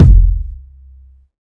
KICK.126.NEPT.wav